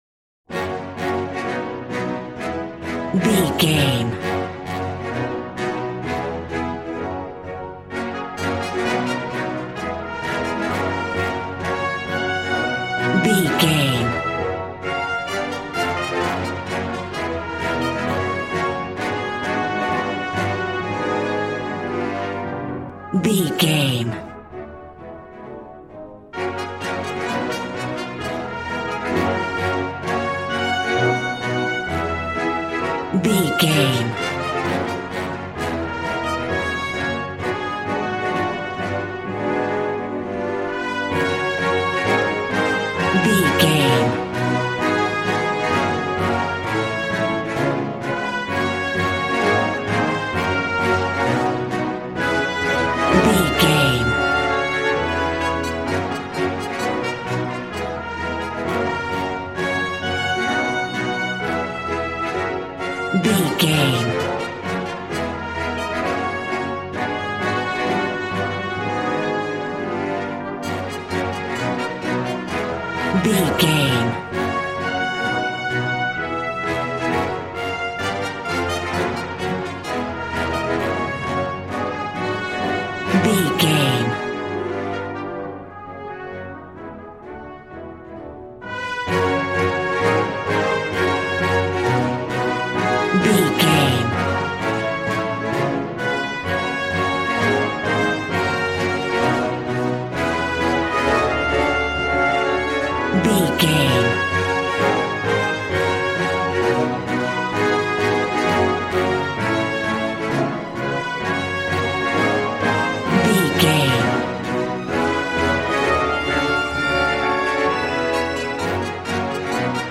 Ionian/Major
G♭
dramatic
powerful
epic
percussion
violin
cello